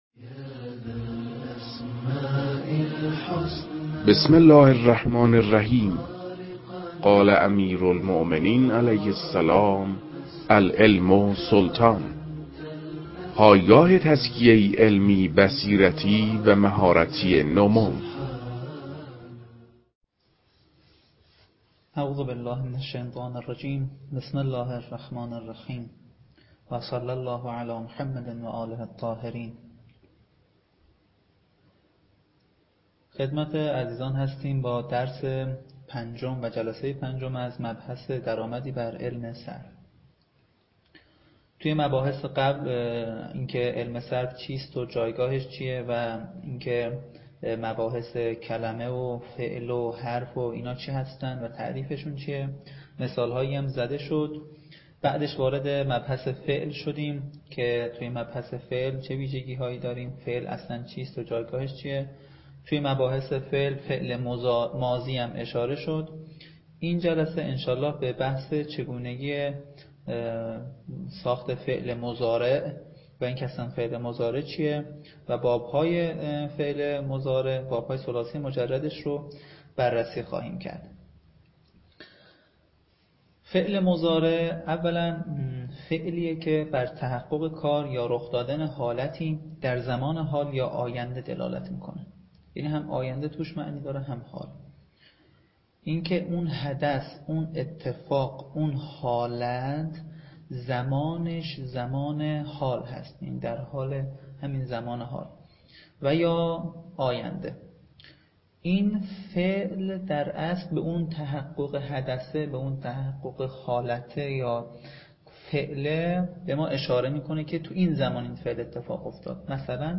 در این بخش، کتاب «درآمدی بر صرف» که اولین کتاب در مرحلۀ آشنایی با علم صرف است، به صورت ترتیب مباحث کتاب، تدریس می‌شود.
در تدریس این کتاب- با توجه به سطح آشنایی کتاب- سعی شده است، مطالب به صورت روان و در حد آشنایی ارائه شود.